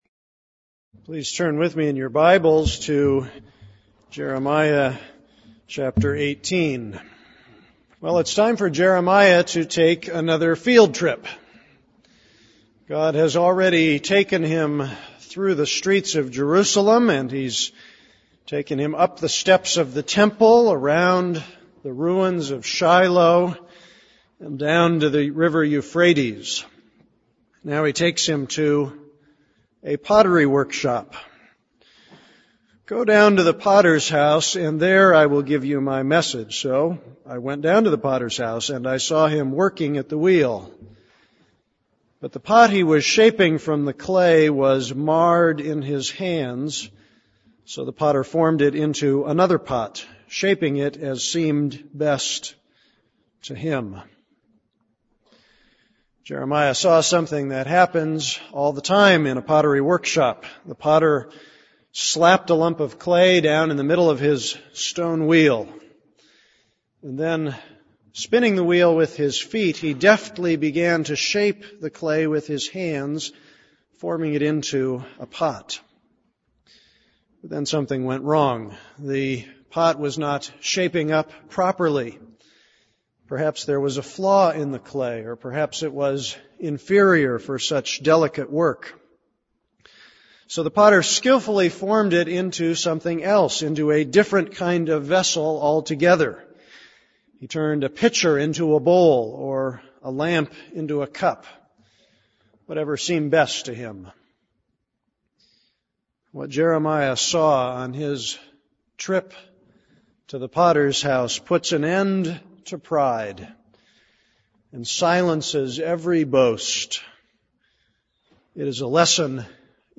This is a sermon on Jeremiah 18:1-23.